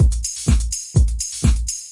描述：125bpm House / tech House / techno loop in FL10。
Tag: 循环 125-BPM 的PERC 舞蹈 房子循环 鼓环 percussion-循环 房子 节拍